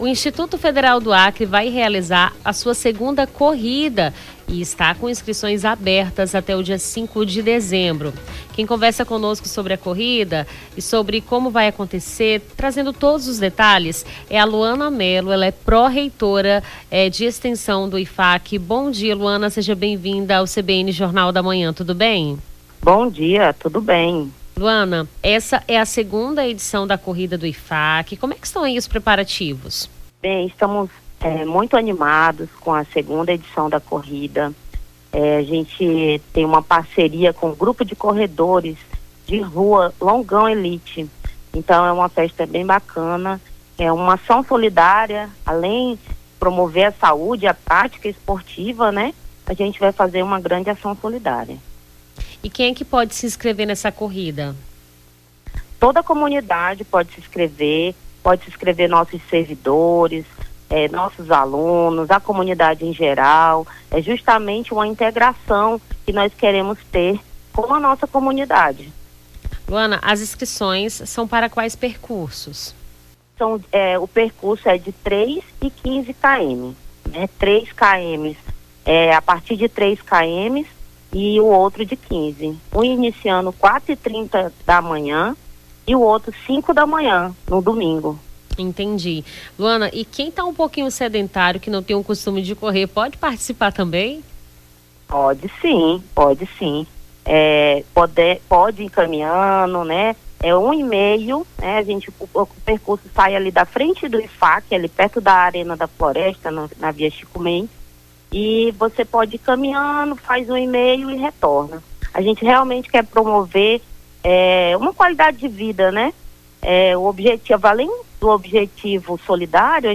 Nome do Artista - CENSURA - ENTREVISTA CORRIDA IFAC (04-12-24).mp3